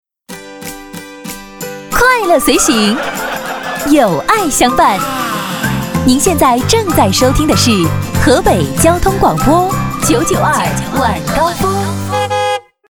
职业配音员全职配音员童声配音
• 女S155 国语 女声 广告-河北992晚高峰-电台片花 积极向上|时尚活力|亲切甜美|素人